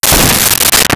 Rifle Shot
Rifle Shot.wav